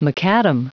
Prononciation du mot macadam en anglais (fichier audio)
Prononciation du mot : macadam